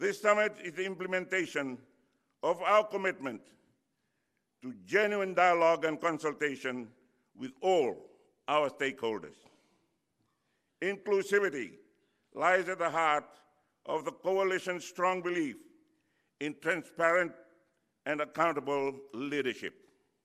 Prime Minister Sitiveni Rabuka during the opening ceremony of the 2023 National Economic Summit this morning.